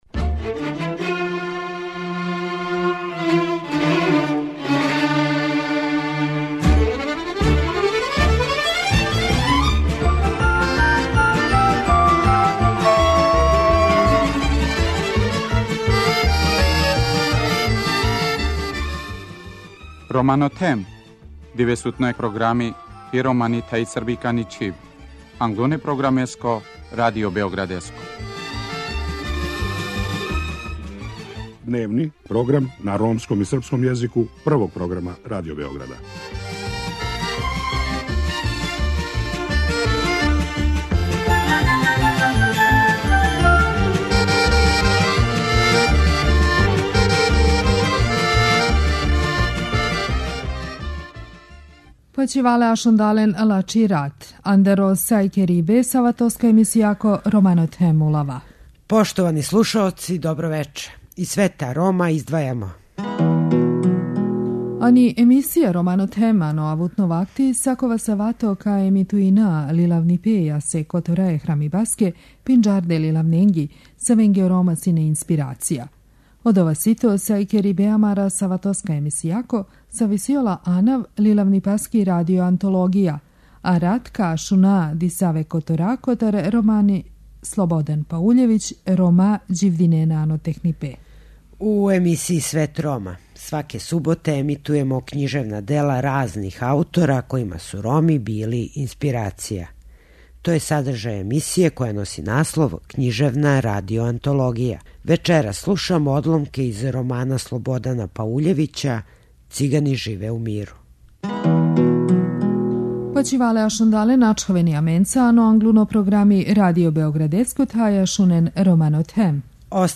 Вечерас слушамо одломке из романа Слободана Пауљевића "Цигани живе у миру".